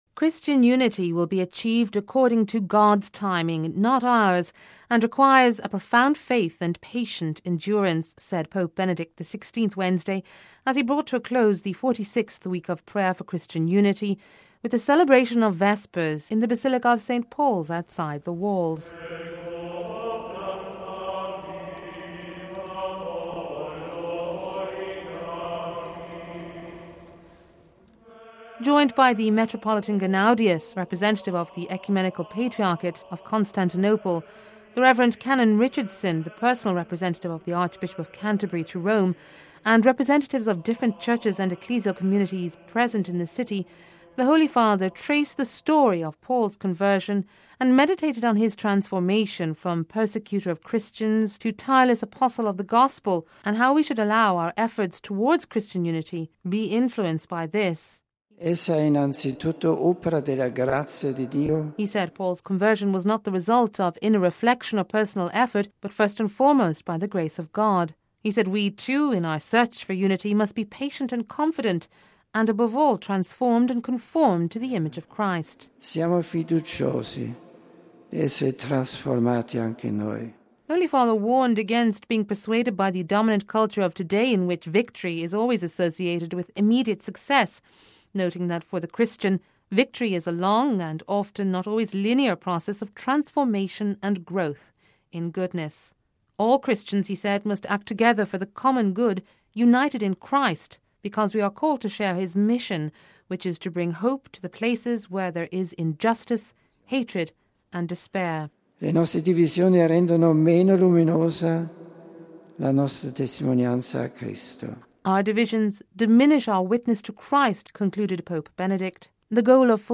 Bringing to a close the Week of Prayer for Christian Unity, the Pope celebrated Vespers in the ancient Church dedicated to a man who from zealous persecutor of Christians, was “transformed into a tireless apostle of the Gospel of Jesus Christ”.